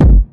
GS Phat Kicks 024.wav